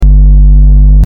Synthwave Hum 04
Synthwave_hum_04.mp3